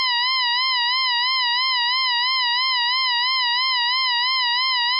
Vibrato.mp3